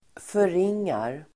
Uttal: [föring:ar]